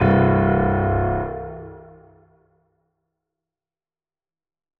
328d67128d Divergent / mods / Hideout Furniture / gamedata / sounds / interface / keyboard / piano / notes-00.ogg 48 KiB (Stored with Git LFS) Raw History